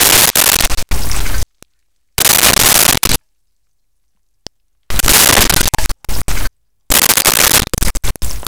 Splashes Flat Hits 01
Splashes Flat Hits 01.wav